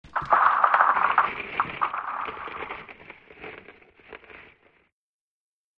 Звуки заморозки
Звук застывания массивных объектов